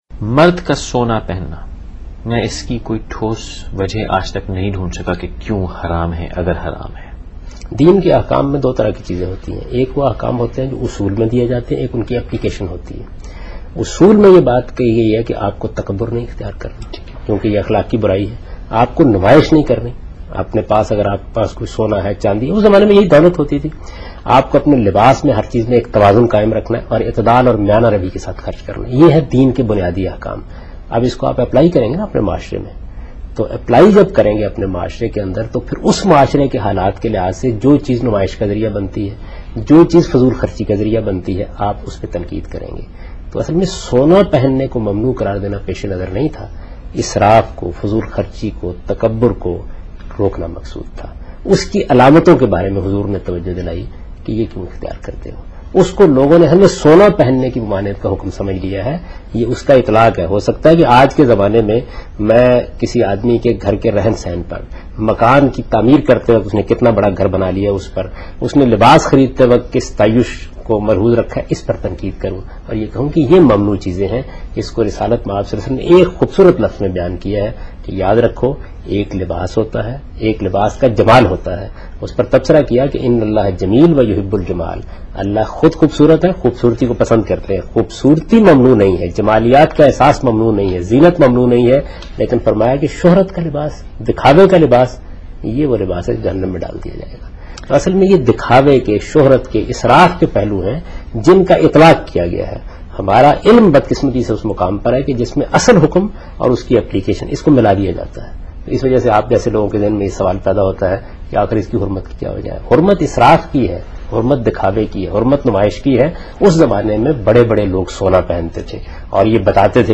Category: TV Programs / Samaa Tv / Questions_Answers /
Javed Ahmad Ghamidi answers "Why men cannot Wear Gold?" in Ankahi show on Samaa tv.